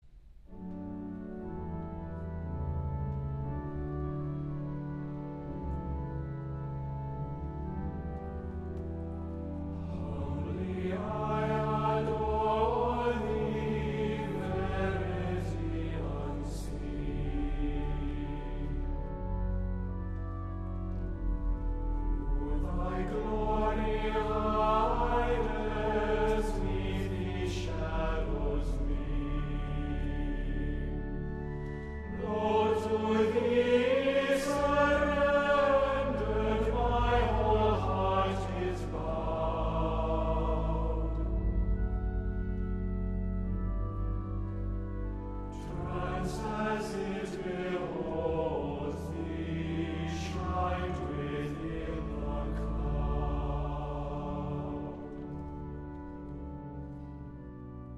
• Music Type: Choral
• Voicing: 2-Part Choir, SA, TB
• Accompaniment: Organ
*Lovely, easy setting of a classic chant
*Discreet organ part supports the singers